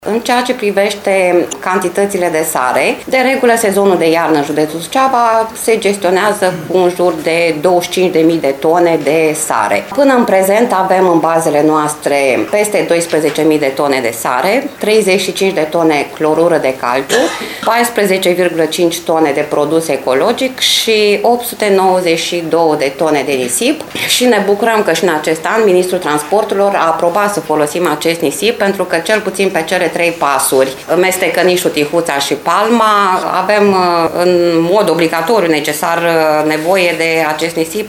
Prefectul MIRELA ADOMNICĂI a declarat astăzi că Secțiile de Drumuri Naționale Suceava și Câmpulung Moldovensc dispun de 46 de utilaje proprii și închiriate pentru deszăpezirea celor 630 kilometri de drumuri naționale.